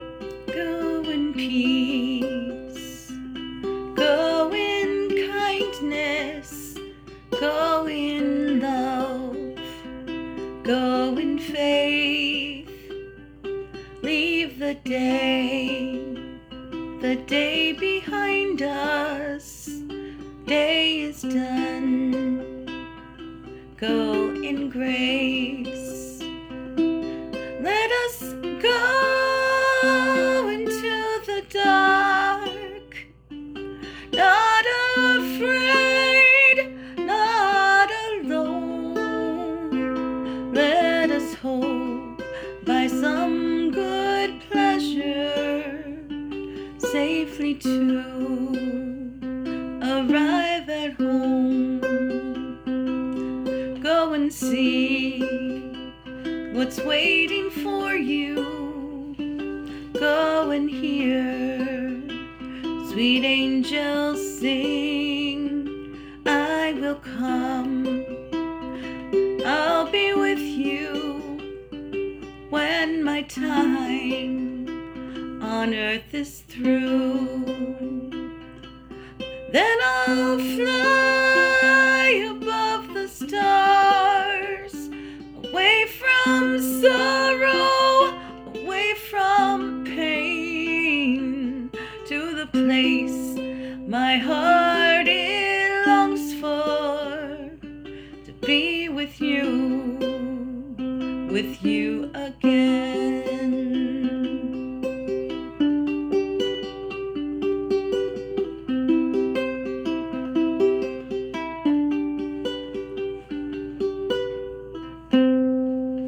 great singing too